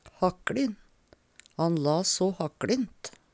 hakkLin - Numedalsmål (en-US)
Hør på dette ordet Ordklasse: Adjektiv Kategori: Karakteristikk Attende til søk